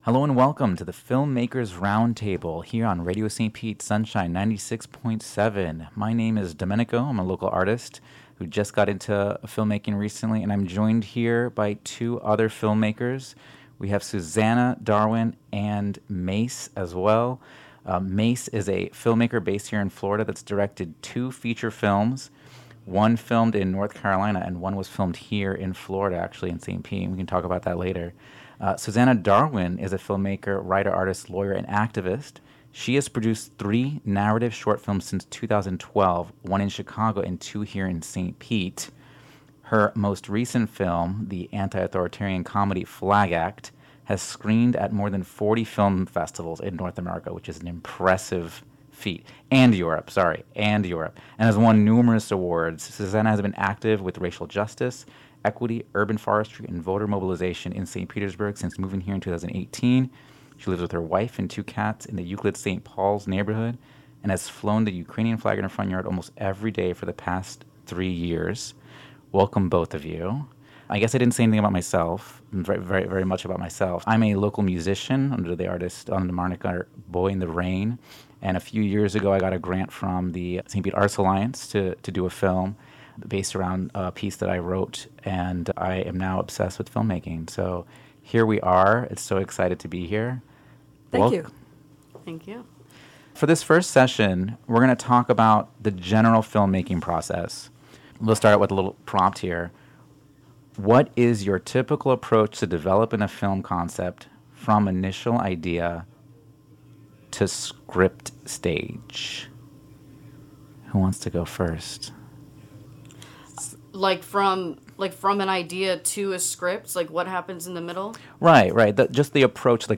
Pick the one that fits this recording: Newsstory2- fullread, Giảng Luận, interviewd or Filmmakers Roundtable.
Filmmakers Roundtable